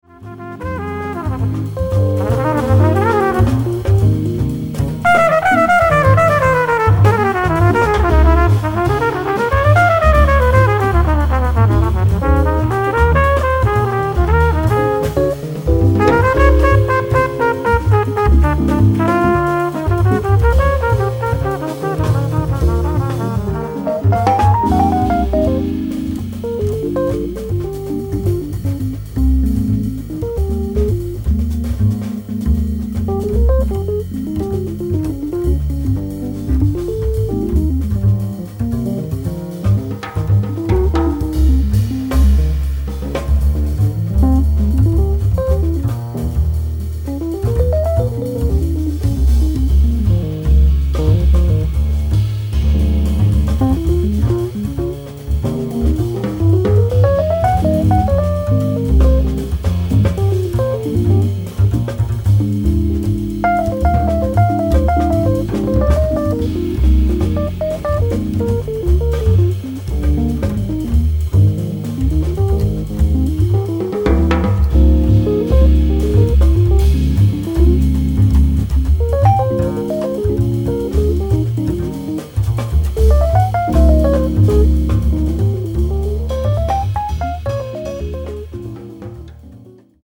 guitar
Fender Rhodes
bass
drums